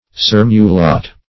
surmulot - definition of surmulot - synonyms, pronunciation, spelling from Free Dictionary Search Result for " surmulot" : The Collaborative International Dictionary of English v.0.48: Surmulot \Sur"mu*lot\, n. [F.]